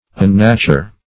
Unnature \Un*na"ture\, v. t. [1st pref. un- + nature.]